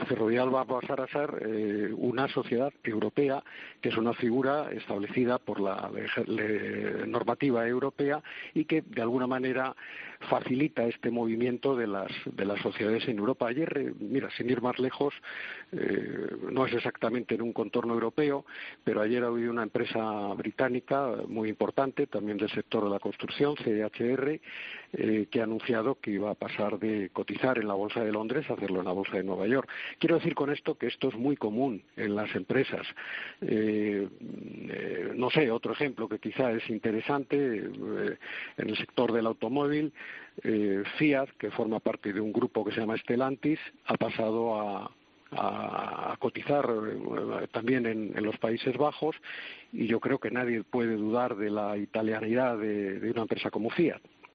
Ferrovial expone en 'Herrera en COPE' otras empresas que también se fueron a Países Bajos